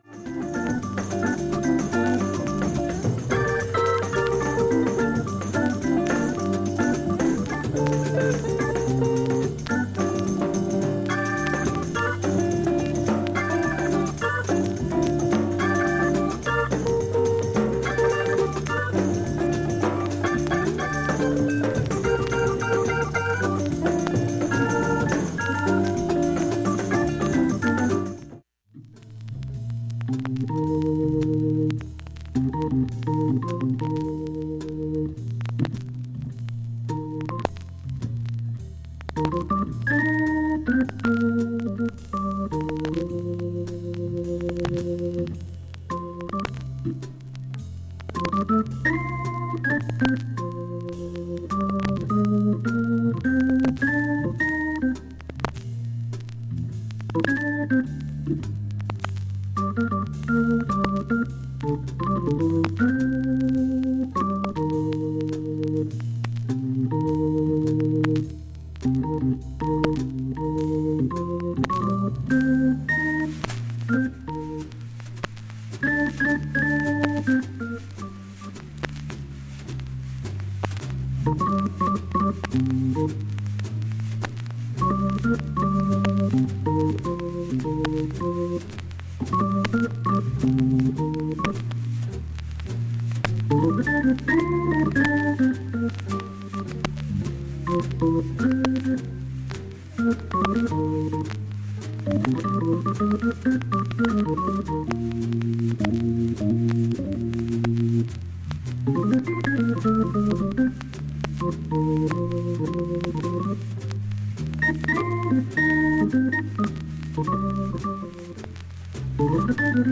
バックチリ小さめなので、
少しチリ出る程度です
プツ自体はストレスはありません。
SHURE M 44G 針圧３グラムで
針飛びはありませんでした。
９５秒の間チャッという感じの音が出ます。
中盤までかすかなチリ、プツ出ます。
１２回までのかすかなプツが８箇所
◆ＵＳＡ盤オリジナル Stereo